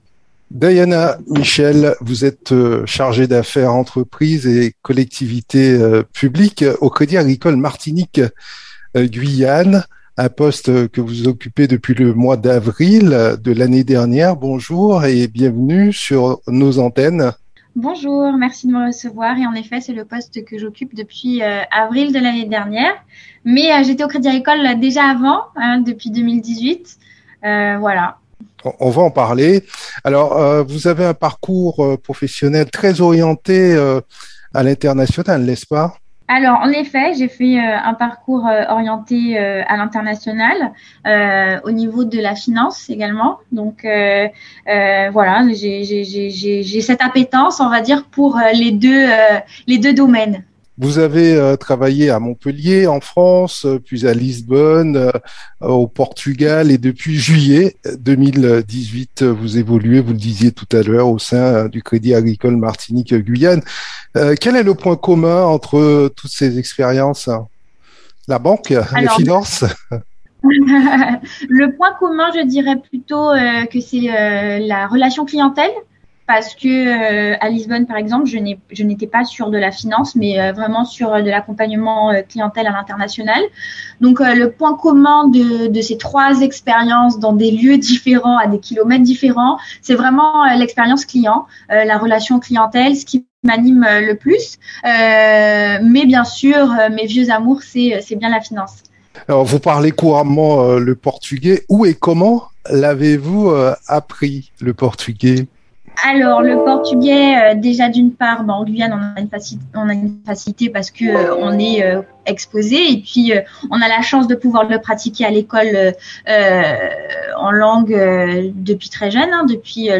Interview.